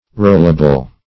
Rollable \Roll"a*ble\ (r[=o]l"[.a]*b'l), a. Capable of being rolled.